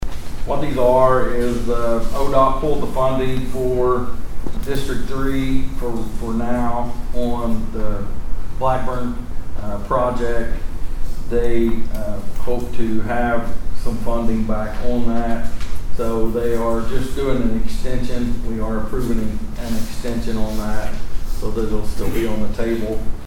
The Osage County Commissioners met in their weekly meeting and discussed a contract renewal for District 3 involving ODOT.